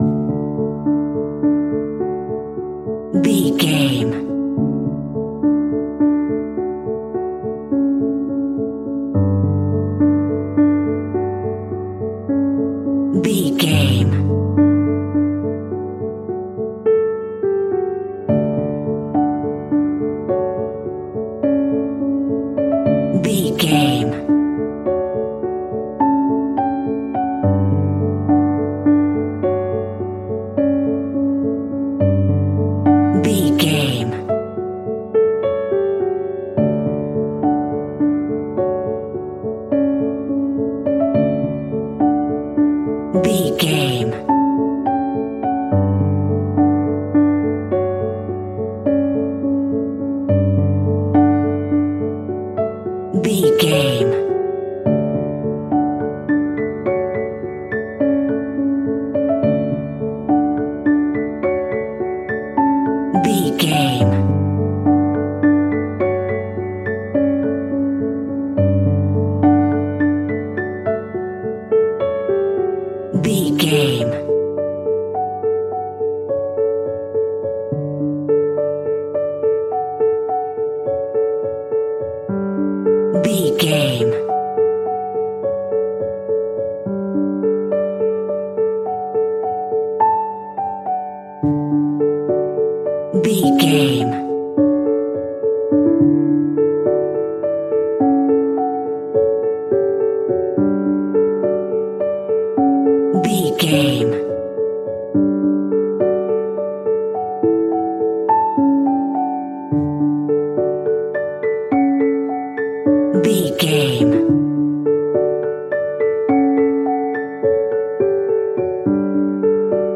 Ionian/Major
WHAT’S THE TEMPO OF THE CLIP?
soft